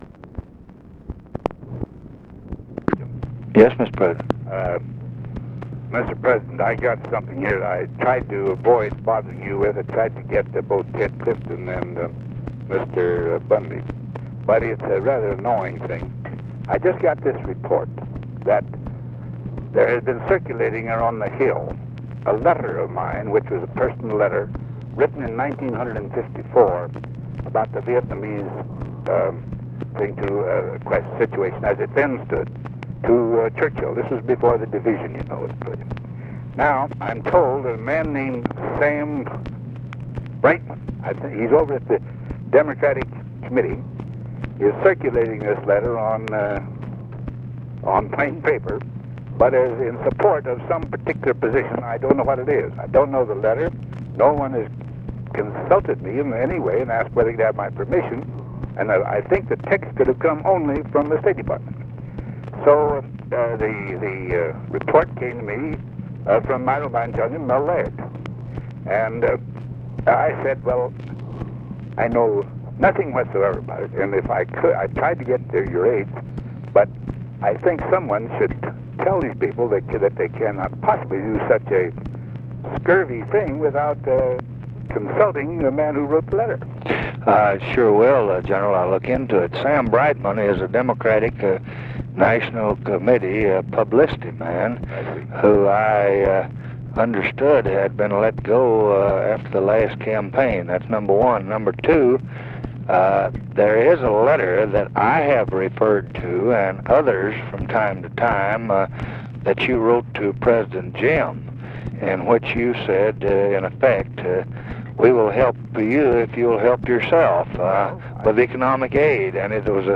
Conversation with DWIGHT EISENHOWER, June 29, 1965
Secret White House Tapes